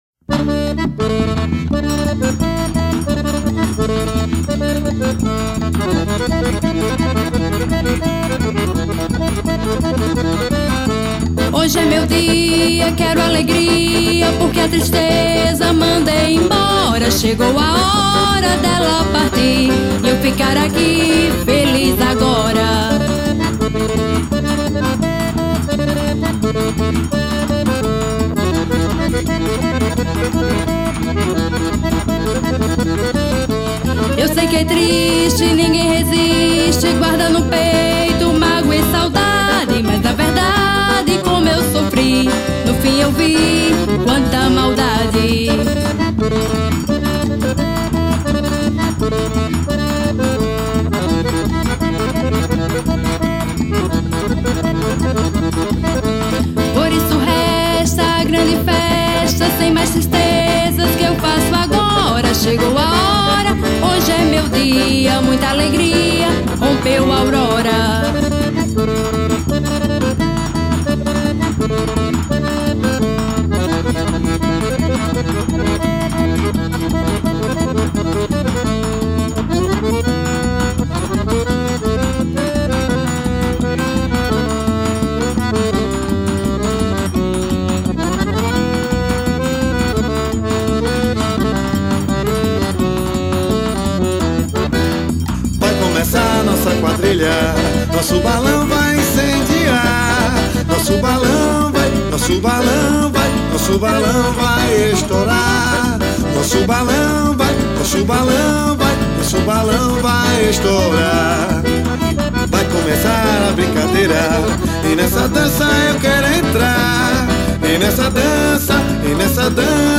Composição: FORRÓ.